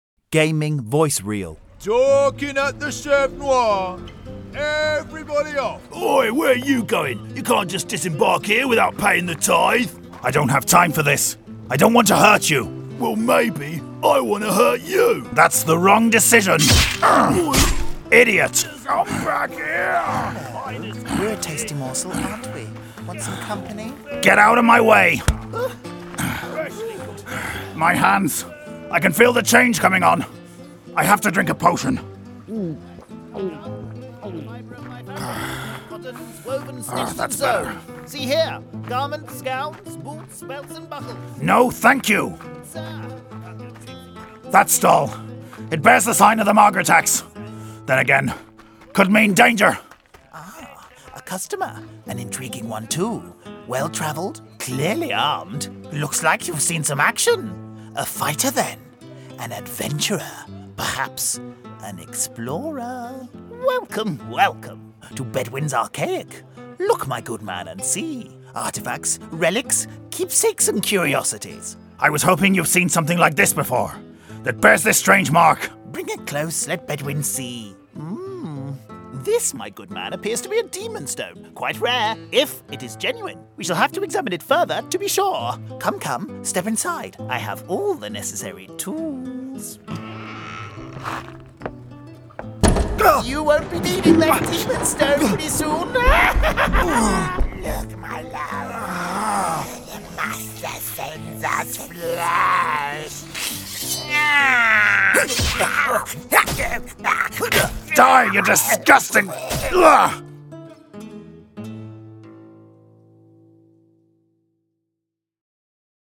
A deep male professional voice, with a 30-60 age range, warm, authoritative, relatable & conversational performances ideal for Christian content and epic Voice of God styles.